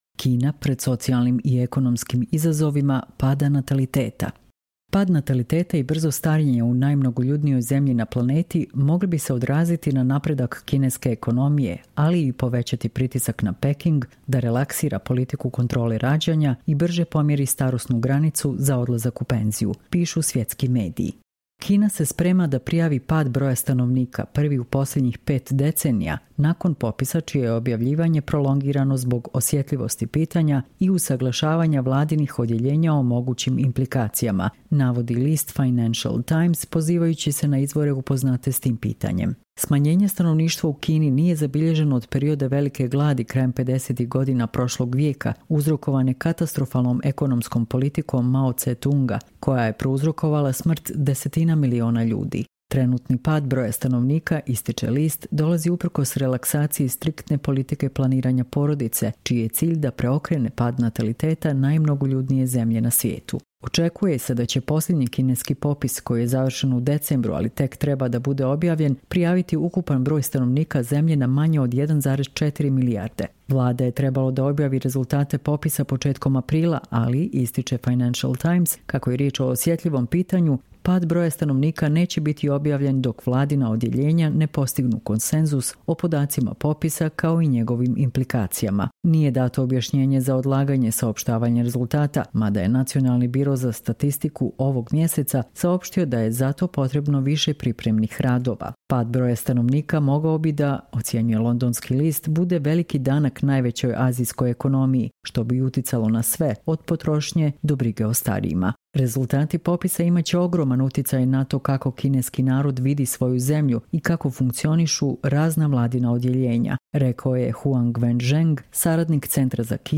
Čitamo vam: Kina pred socijalnim i ekonomskim izazovima pada nataliteta